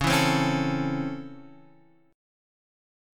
C#mM7#5 chord